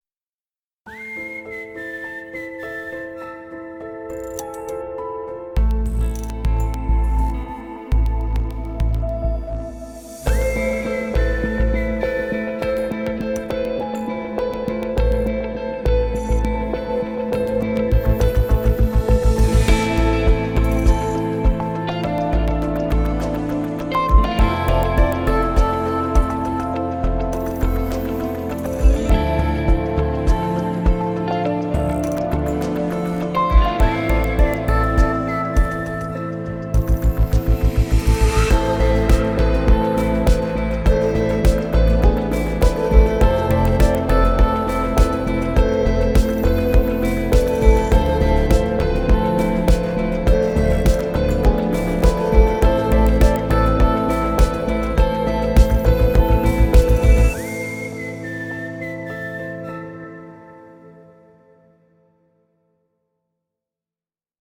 tema dizi müziği, duygusal hüzünlü heyecan fon müziği.